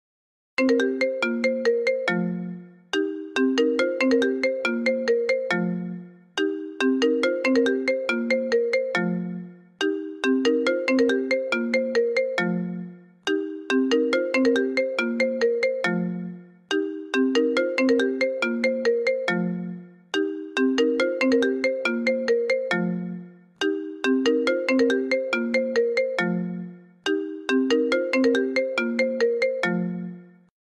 เสียงเรียกเข้าไอโฟน Original
หมวดหมู่: เสียงเรียกเข้า
เสียงเรียกเข้าไอโฟน Original MP3 ที่พร้อมให้ดาวน์โหลดง่าย ๆ ทั้งสำหรับ iPhone และ Android เสียงคมชัด คุณภาพดี เหมาะสำหรับคนที่อยากเพิ่มสไตล์ให้สมาร์ทโฟนของคุณ
nhac-chuong-iphone-original-th-www_tiengdong_com.mp3